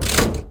lever1.wav